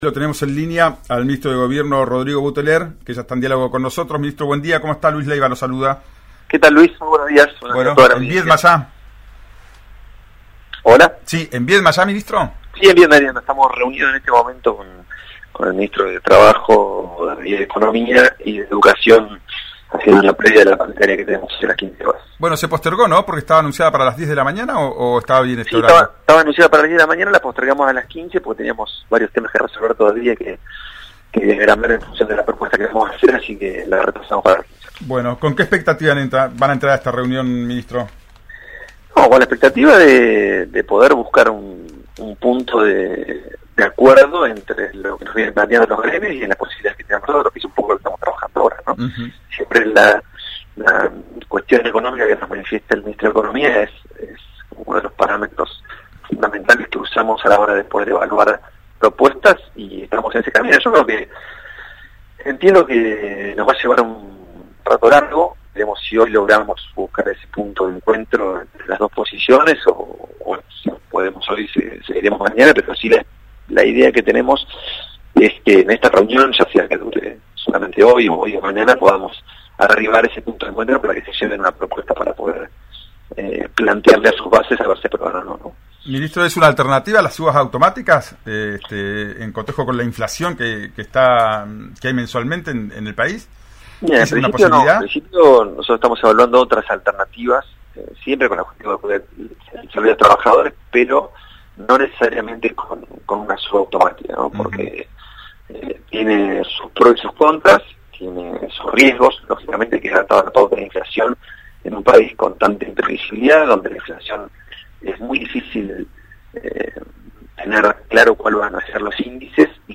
Rodrigo Buteler, ministro de gobierno habló con RÍO NEGRO RADIO y adelantó cómo viene el acuerdo con los gremios y manifestó su deseo de un inicio de clases asegurado.
Escuchá a Rodrigo Buteler en «Ya es tiempo» por RÍO NEGRO RADIO: